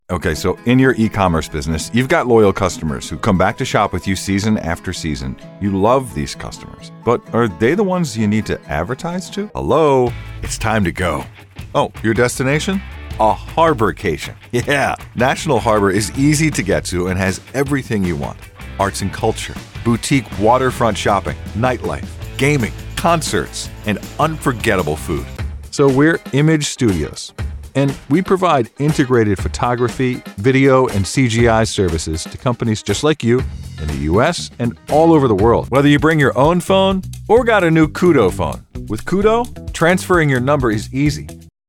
Rugged. Warm
Conversational Guy Next Door
Middle Aged